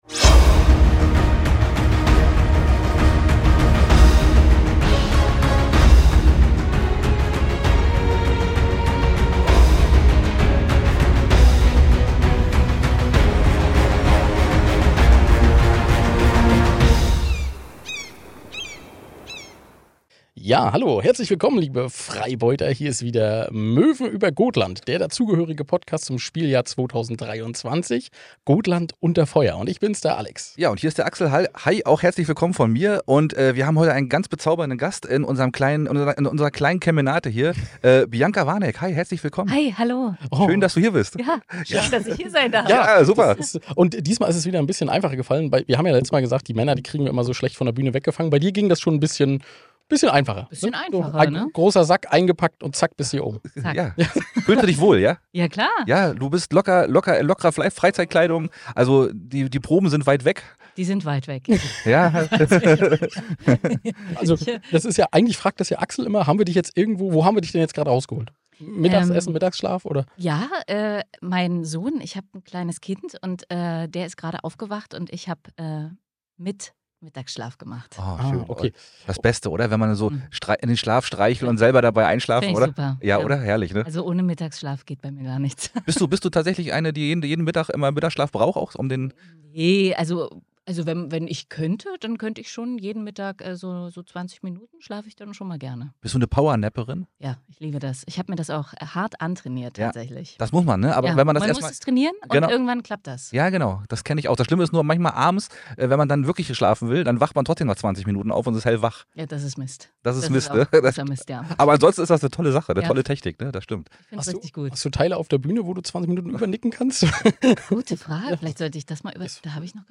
Auch wenn das Mikro manchmal sein eigenes Ding macht
Gespräch